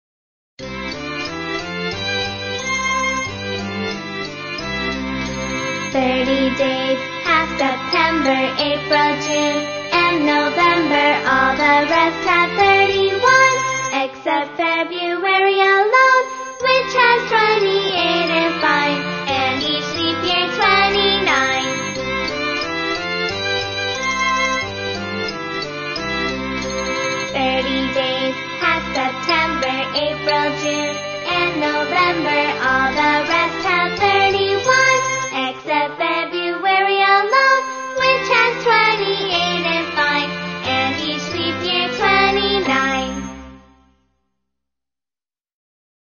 在线英语听力室英语儿歌274首 第28期:Days of the Month的听力文件下载,收录了274首发音地道纯正，音乐节奏活泼动人的英文儿歌，从小培养对英语的爱好，为以后萌娃学习更多的英语知识，打下坚实的基础。